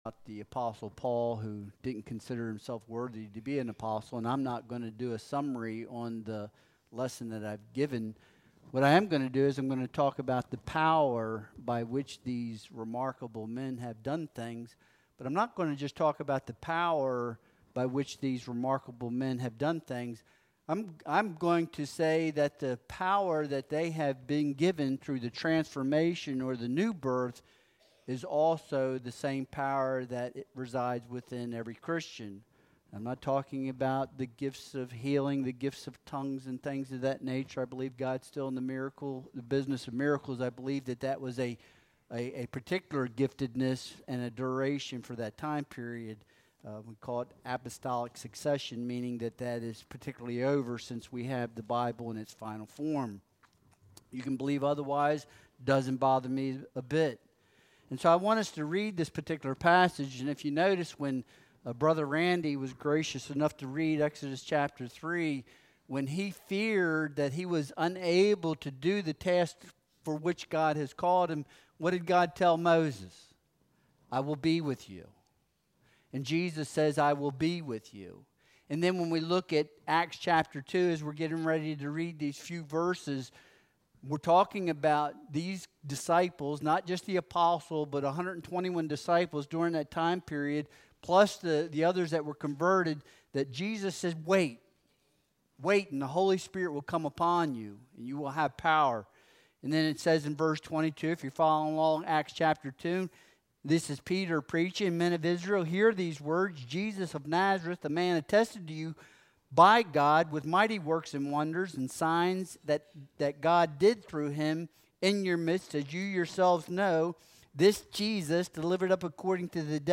Acts 2.22-24 Service Type: Sunday Worship Service Download Files Bulletin « Twin Factor Paul